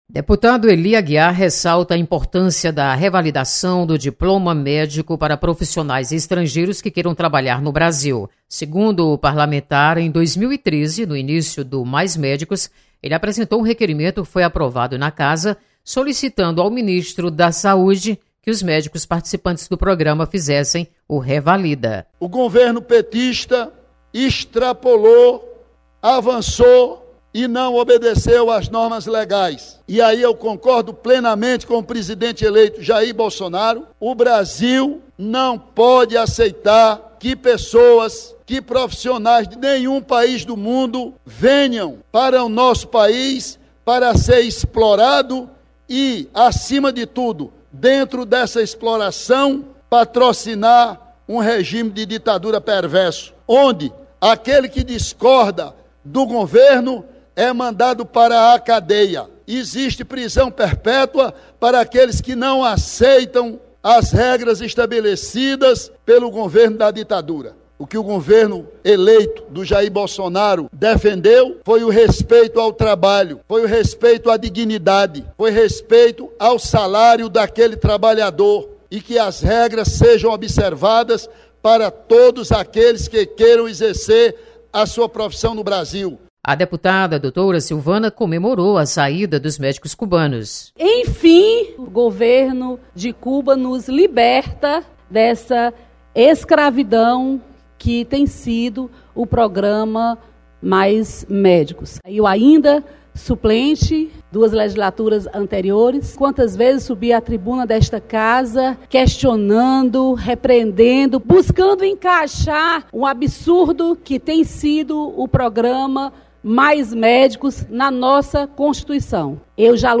Plenário